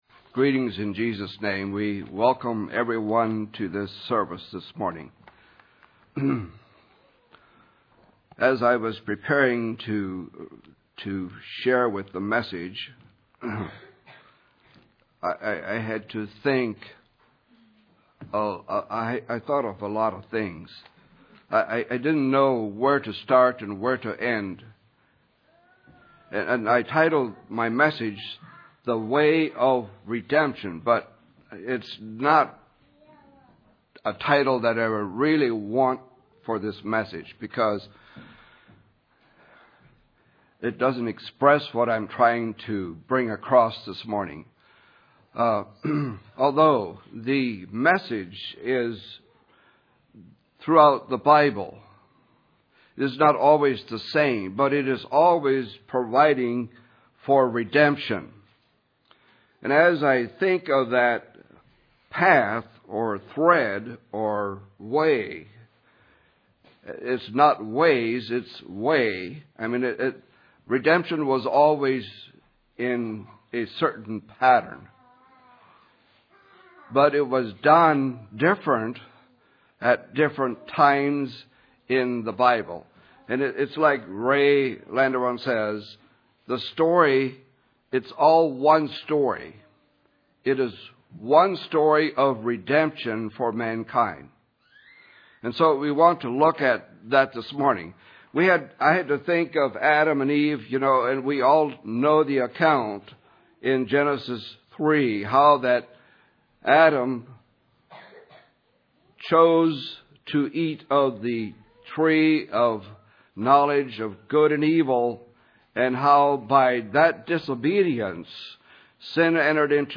Communion service message